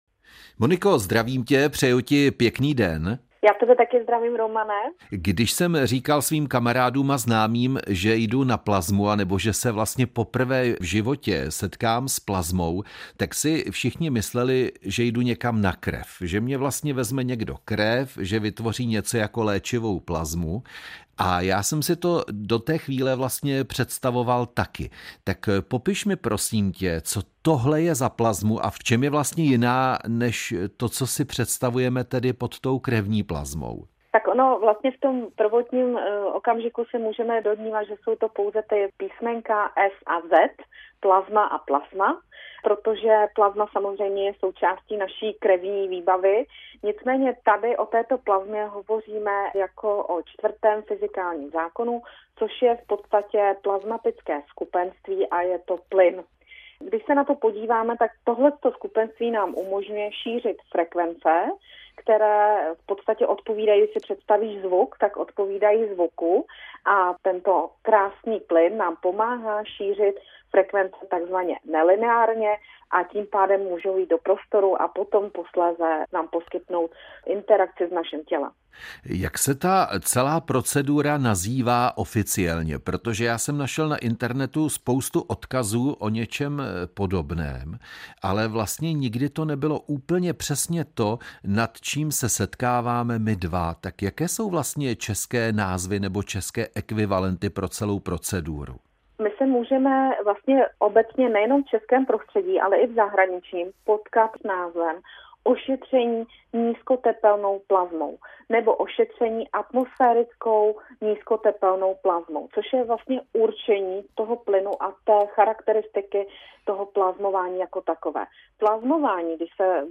rozhovor na téma frekvenční terapie a plazmový generátor